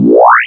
laseron.wav